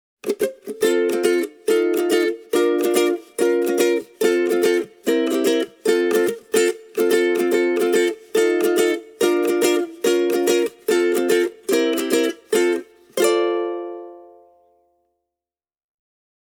The RU5’s neck profile is a rounded D. The playability of this affordable concert model is great.
The Ortega RU5 has a strong and open voice with a nice dose of clarity.